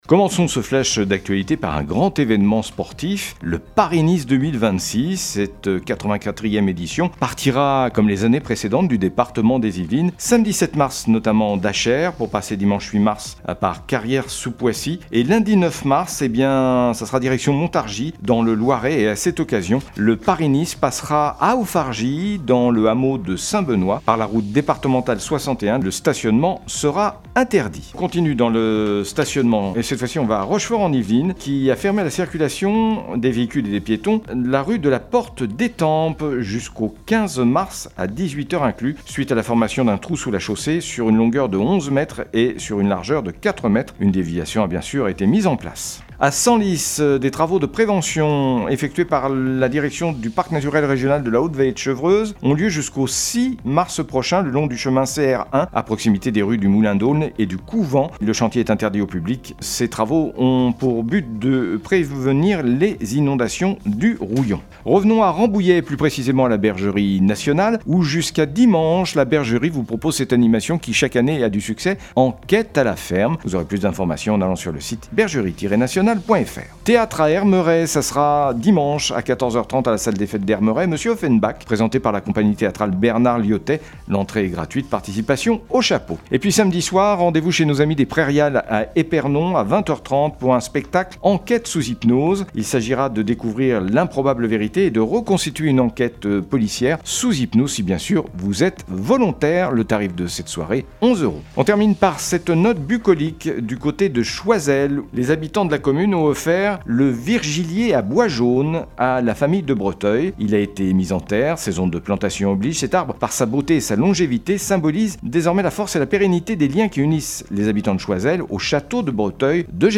05.03-flash-local-matin.mp3